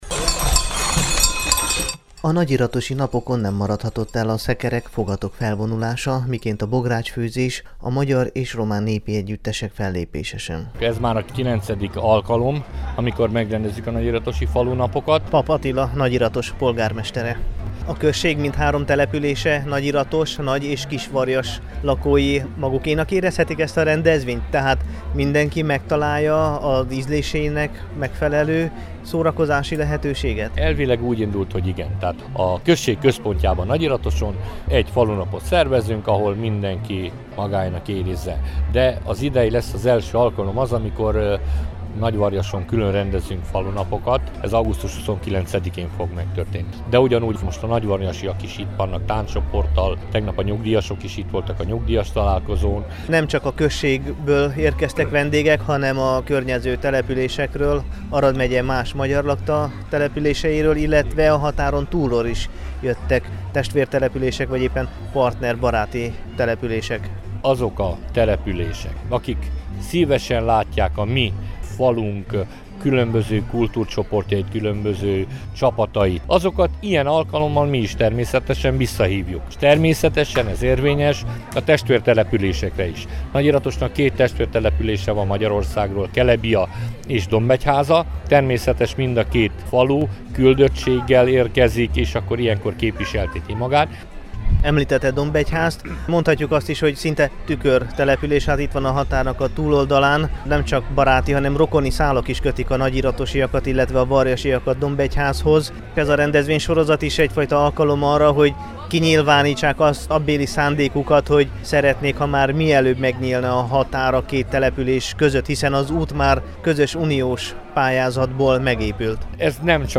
Három éjjel, három nap szólt a nóta, rotyogtak a bográcsok, s tartott a mulatozás a hétvégén az Arad megyei Nagyiratoson.